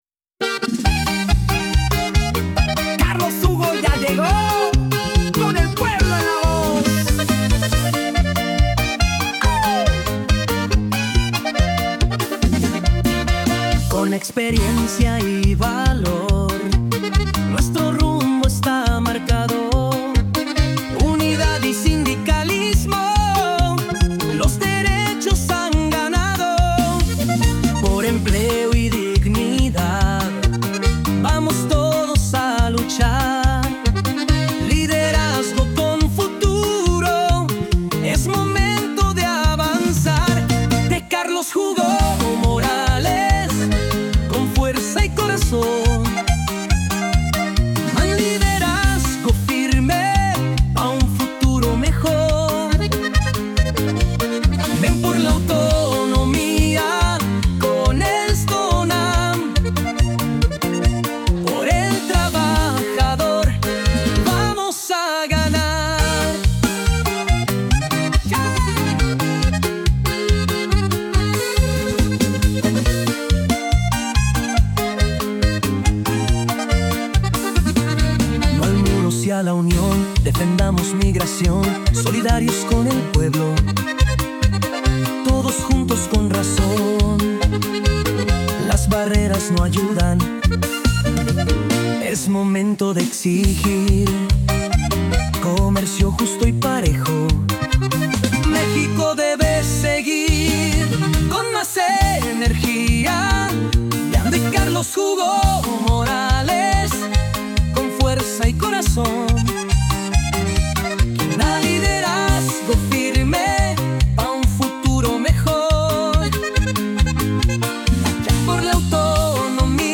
Género: Norteño